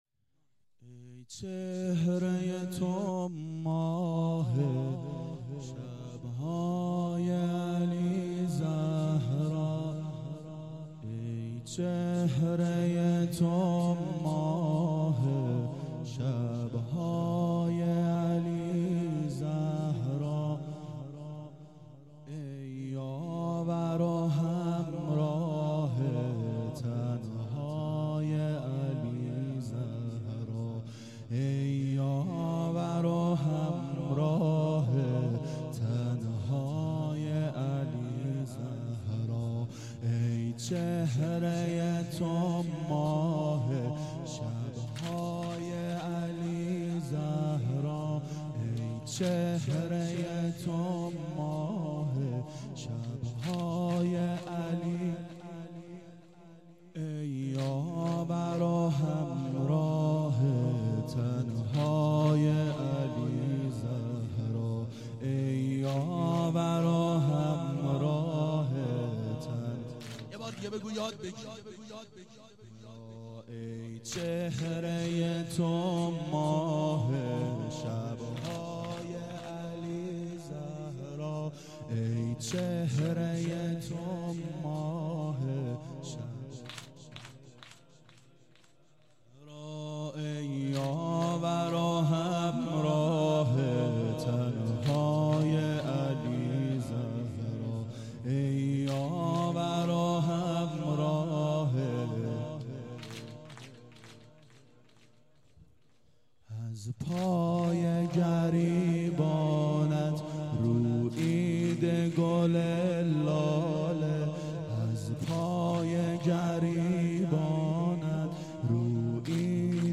• دهه اول صفر سال 1391 هیئت شیفتگان حضرت رقیه سلام الله علیها (شام غریبان)